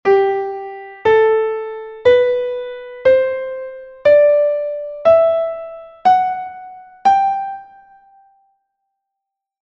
This scale is the same as C major, but instead of beginning in C it begins in G.
escala_de_solM.mp3